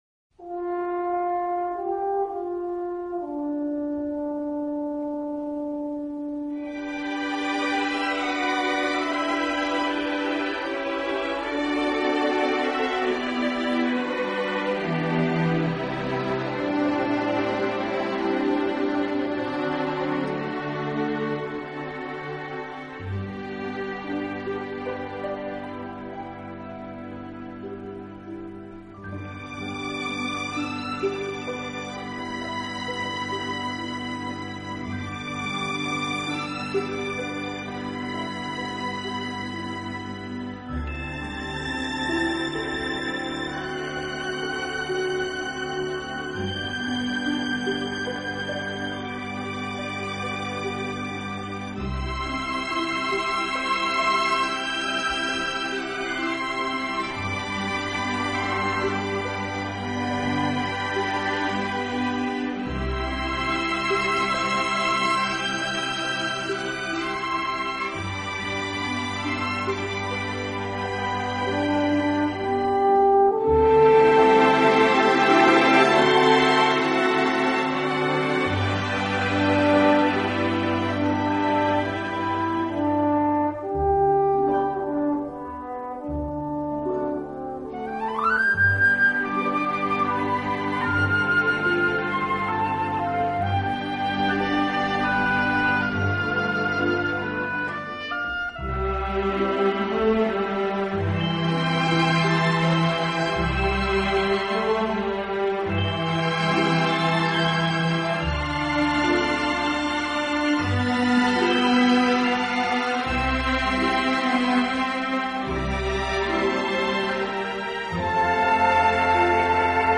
这个乐团的演奏风格流畅舒展，
旋律优美、动听，音响华丽丰满。